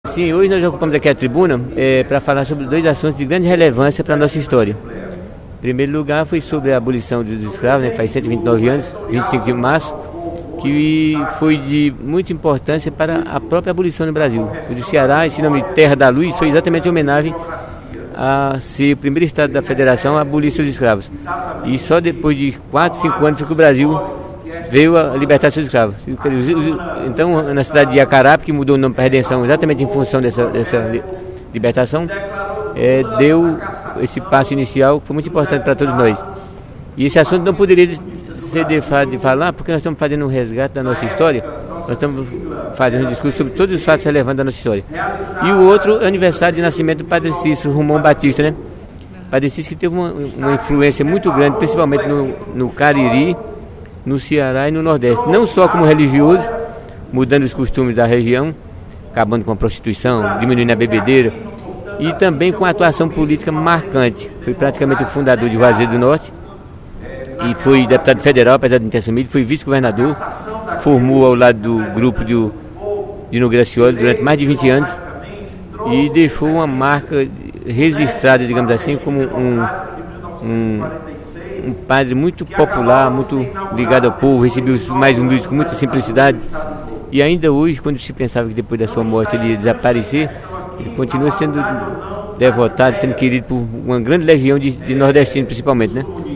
O deputado Paulo Facó (PTdoB) abriu o primeiro expediente desta sexta-feira (22/03) lembrando de importantes datas históricas celebradas este mês, no Ceará. O parlamentar destacou os 129 anos da libertação dos escravos no Estado.